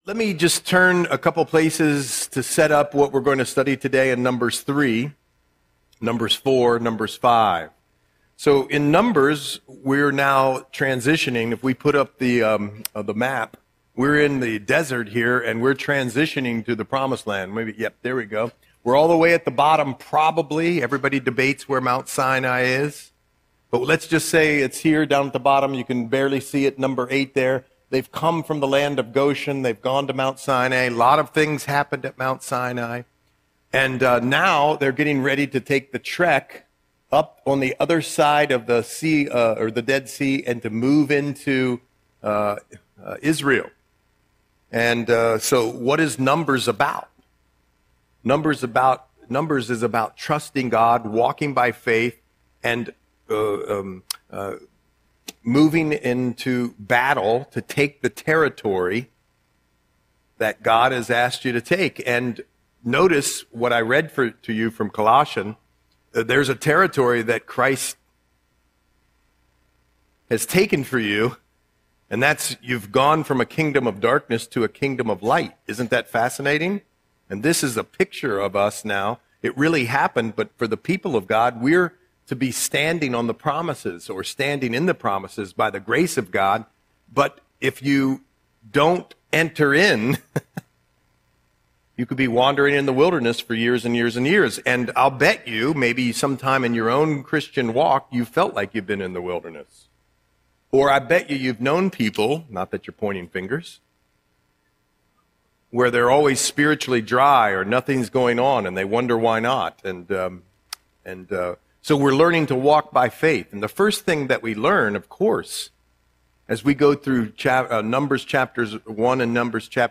Audio Sermon - February 18, 2026